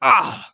ZS被击倒5.wav
ZS被击倒5.wav 0:00.00 0:00.54 ZS被击倒5.wav WAV · 47 KB · 單聲道 (1ch) 下载文件 本站所有音效均采用 CC0 授权 ，可免费用于商业与个人项目，无需署名。
人声采集素材/男3战士型/ZS被击倒5.wav